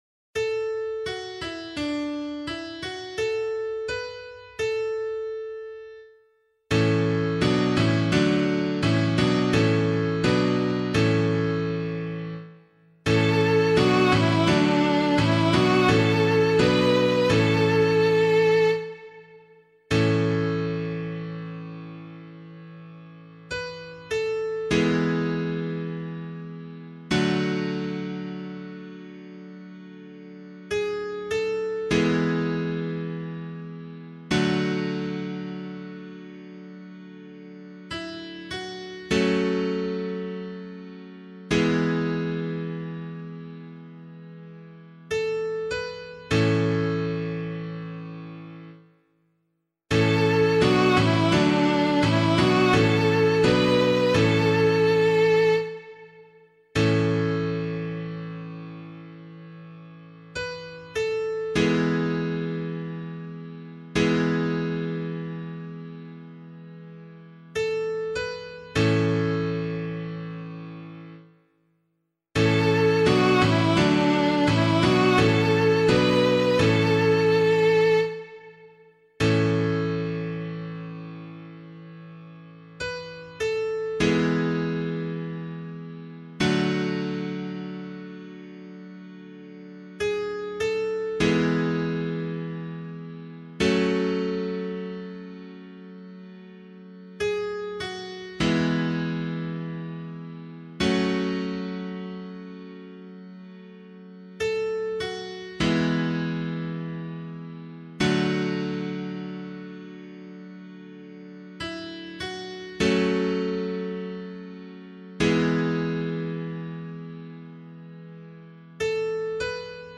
042 Ordinary Time 8 Psalm C [LiturgyShare 8 - Oz] - piano.mp3